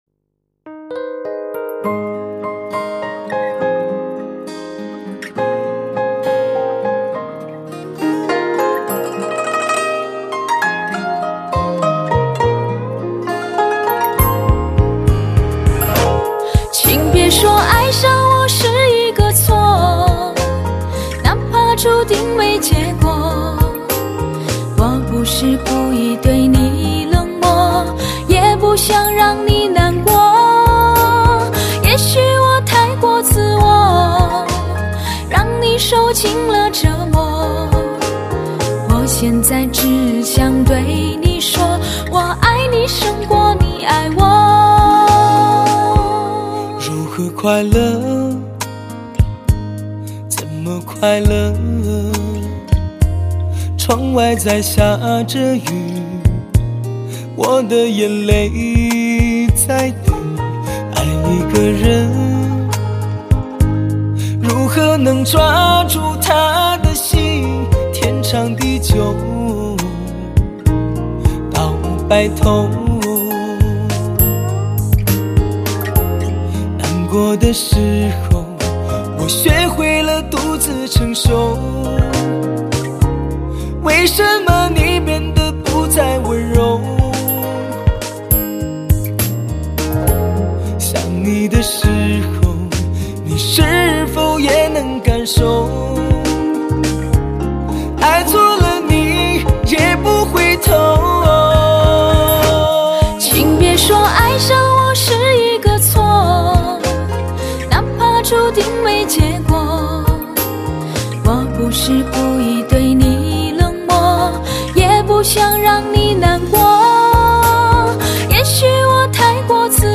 类型: 汽车音乐
音场超级宽广，音效超强动感，人声清晰震撼，节奏令你人神共奋。